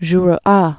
(zhr)